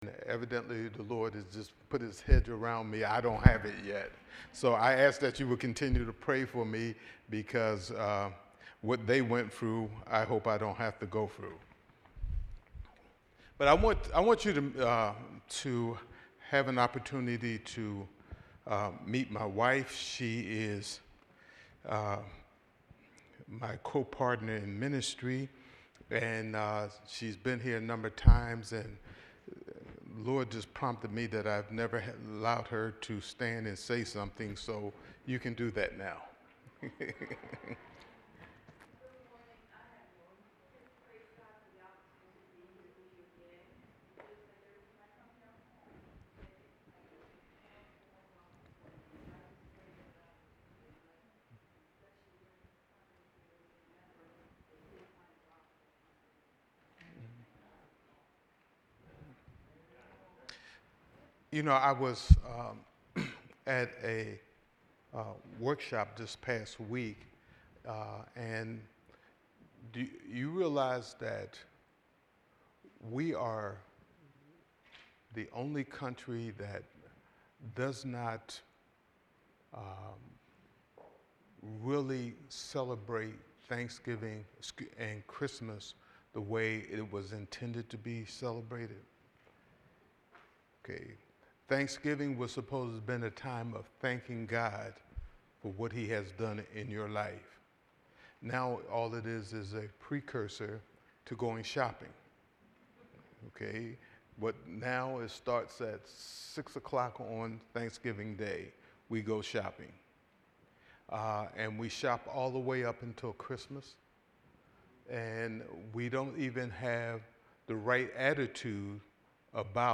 New Sermon